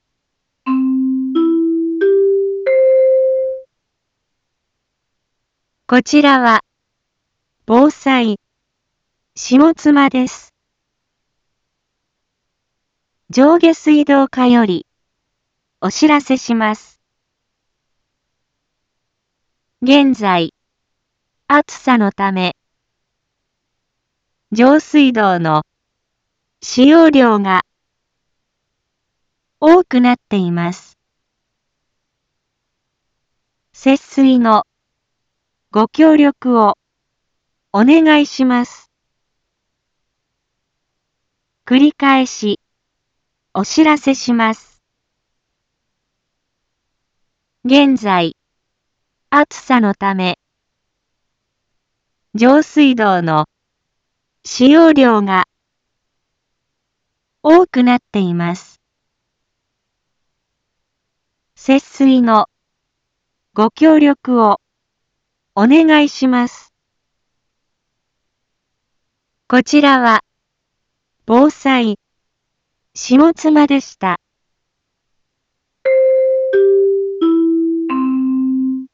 Back Home 一般放送情報 音声放送 再生 一般放送情報 登録日時：2022-07-23 10:01:22 タイトル：節水のお願いについて インフォメーション：こちらは、防災、下妻です。